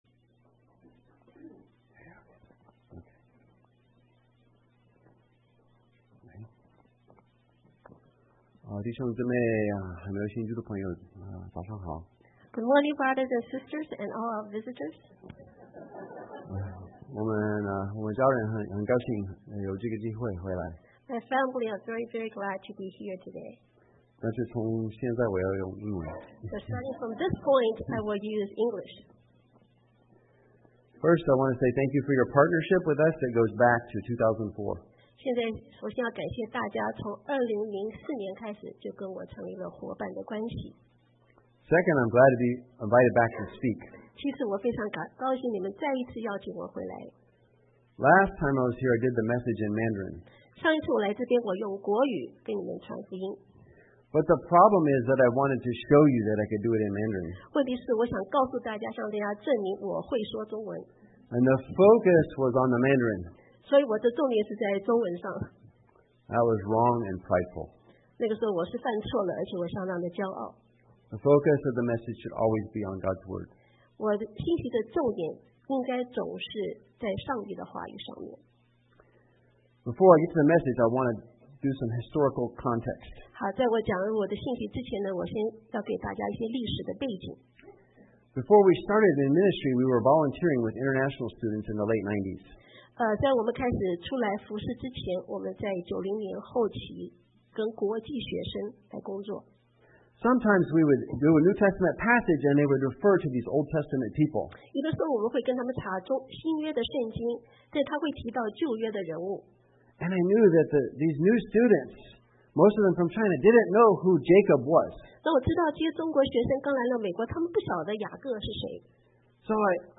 John 15:1-11 Service Type: Sunday AM Bible Text